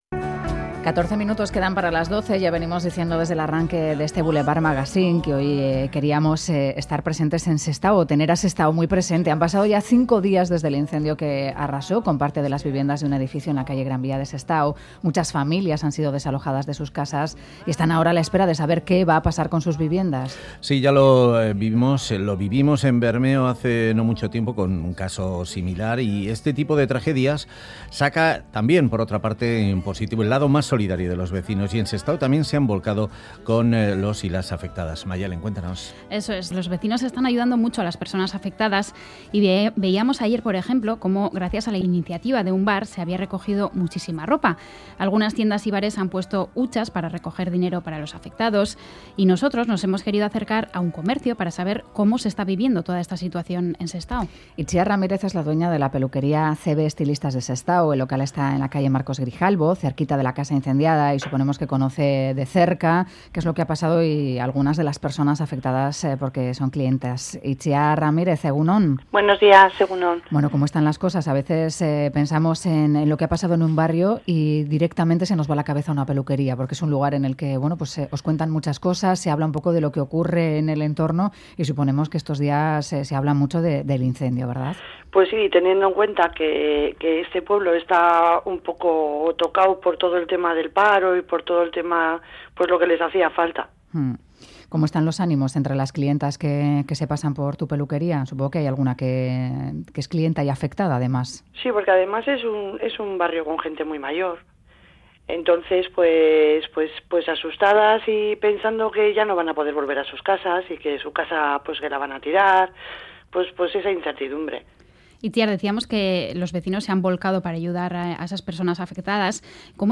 Audio: Hablamos con el alcalde se Sestao sobre la última hora del edificio incendiado el pasado domingo.